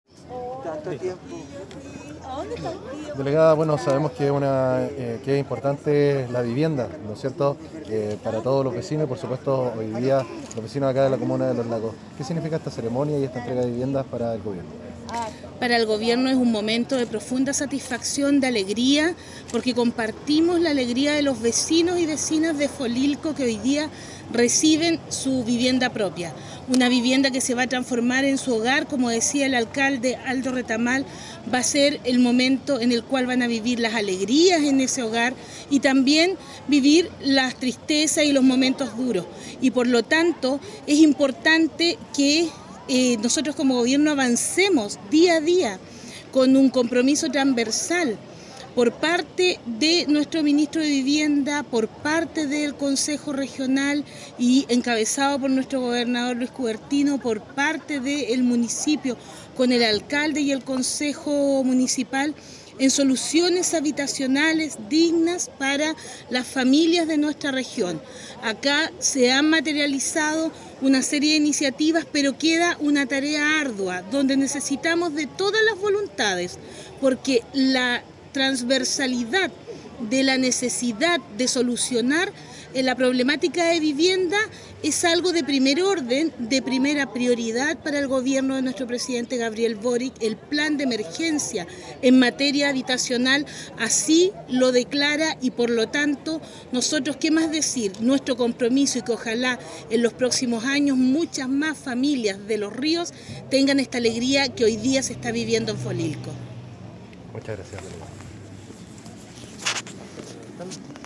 DELEGADA PEÑA